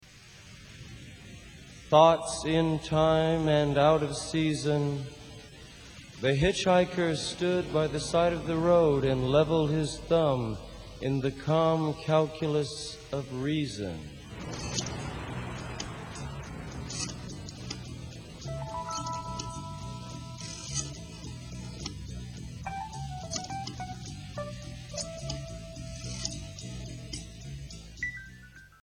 Tags: Jim Morrison quotes The Doors Jim Morrison Poems Jim Morrison spoken word Poetry CD